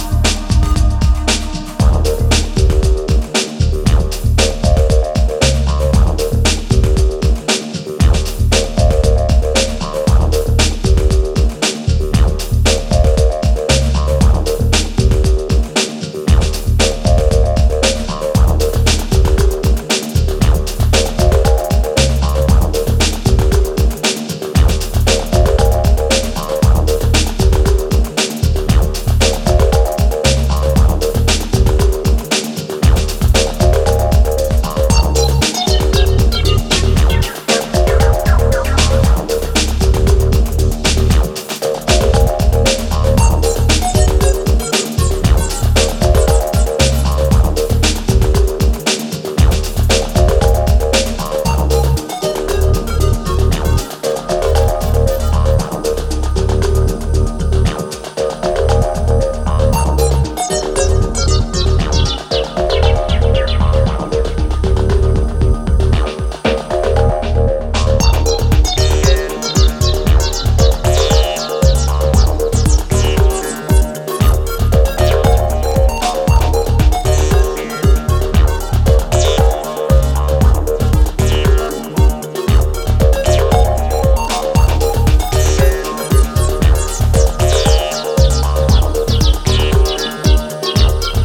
Dub, house, breaks, acid, ambient, progressive… you name it.